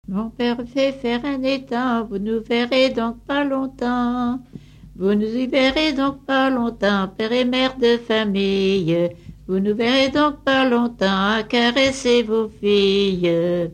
chanson de conscrit
Dompierre-sur-Yon
Pièce musicale inédite